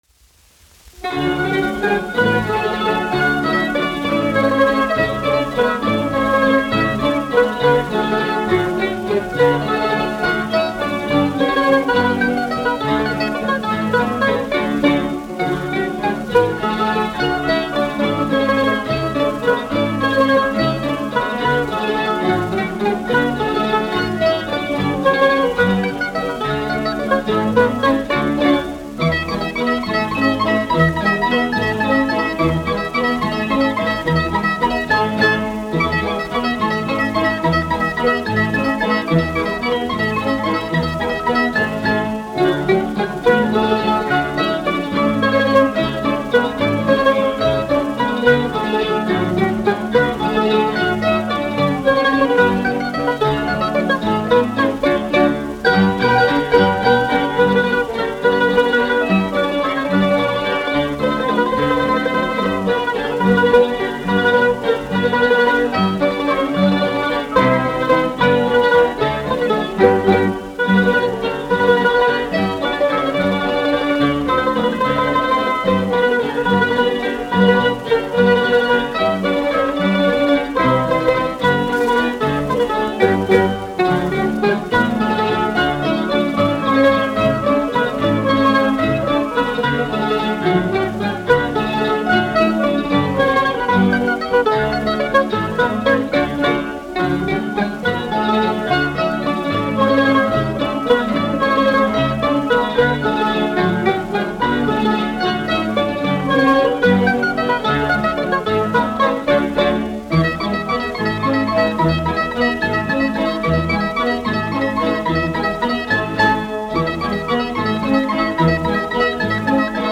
1 skpl. : analogs, 78 apgr/min, mono ; 25 cm
Mazurkas
Orķestra mūzika
Skaņuplate